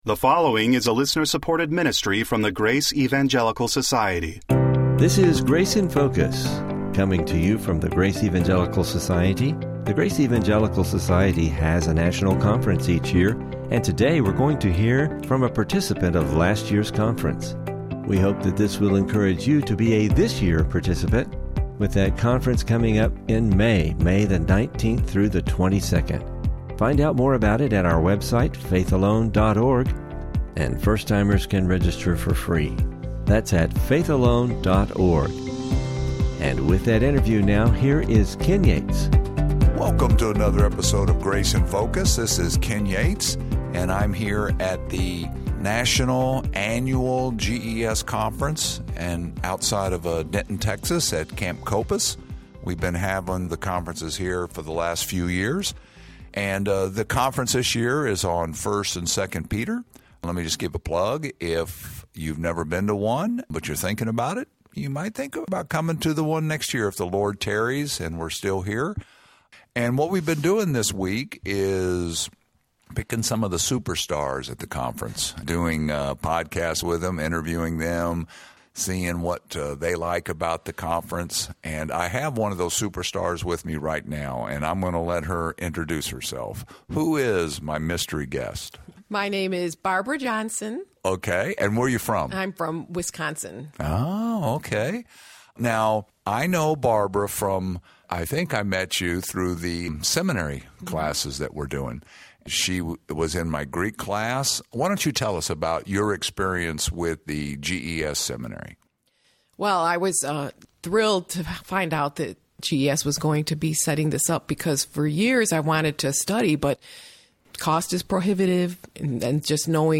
Interview – GES National Conference 2024 Attendees